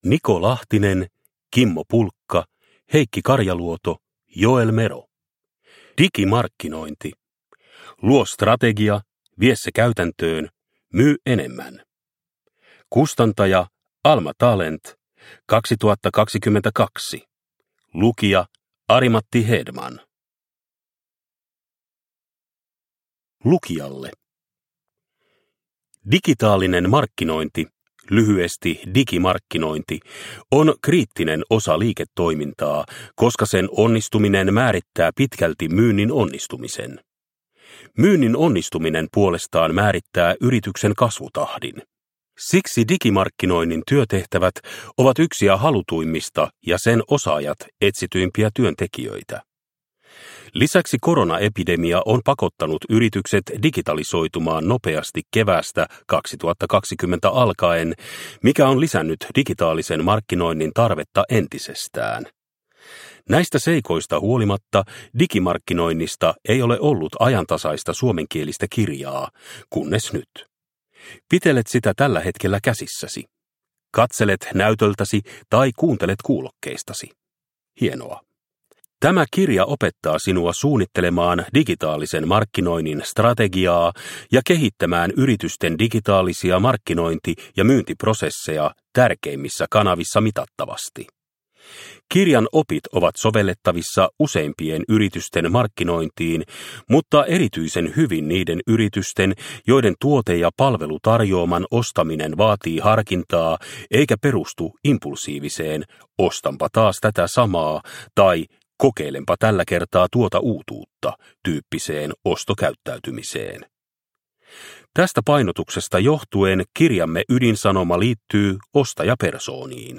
Digimarkkinointi – Ljudbok – Laddas ner